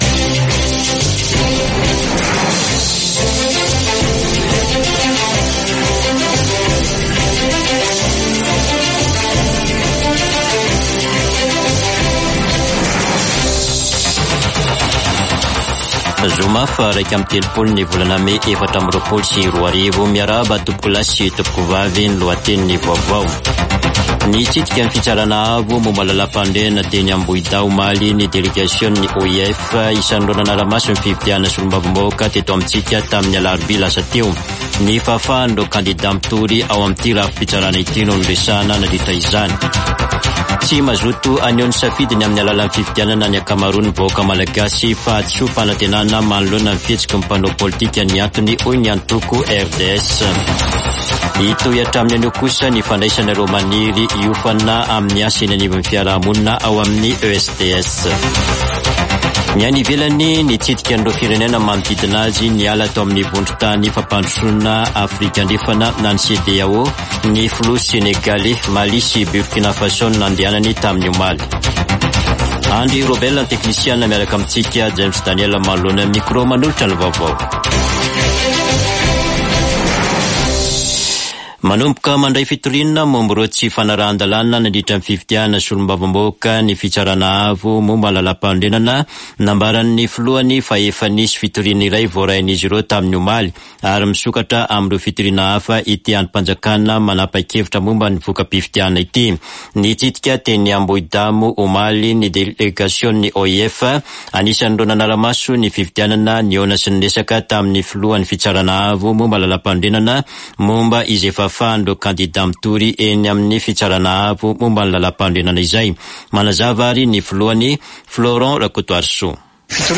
[Vaovao maraina] Zoma 31 mey 2024